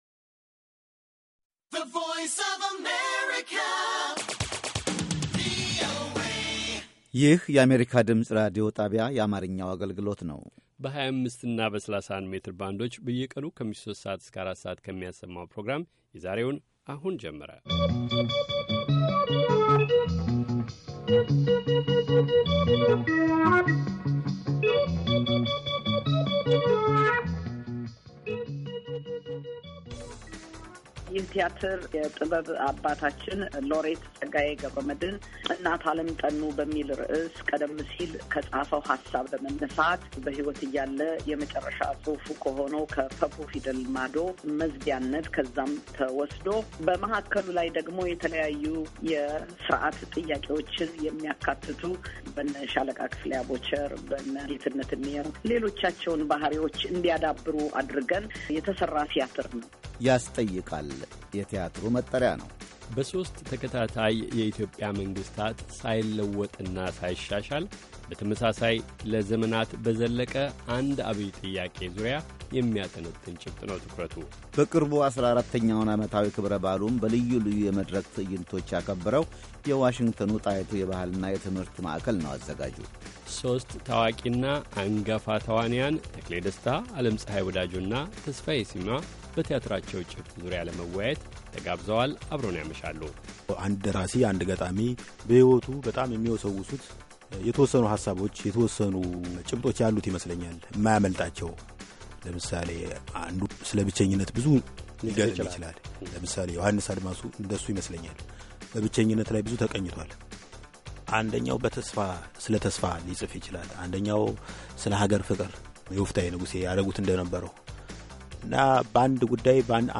ከምሽቱ ሦስት ሰዓት የአማርኛ ዜና
ቪኦኤ በየዕለቱ ከምሽቱ 3 ሰዓት ጀምሮ በአማርኛ፣ በአጭር ሞገድ 22፣ 25 እና 31 ሜትር ባንድ ከሚያሠራጨው የ60 ደቂቃ ዜና፣ አበይት ዜናዎች ትንታኔና ሌሎችም ወቅታዊ መረጃዎችን የያዙ ፕሮግራሞች በተጨማሪ ከሰኞ እስከ ዐርብ ከምሽቱ 1 ሰዓት እስከ 1 ሰዓት ተኩል በአማርኛ የሚተላለፍ የግማሽ ሰዓት ሥርጭት በ1431 መካከለኛ ሞገድ ላይ አለው፡፡